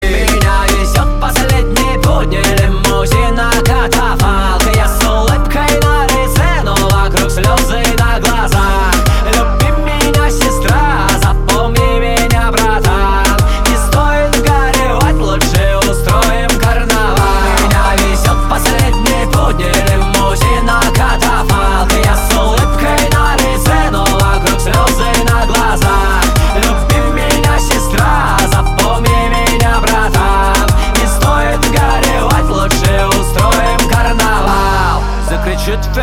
• Качество: 320, Stereo
ритмичные
мрачные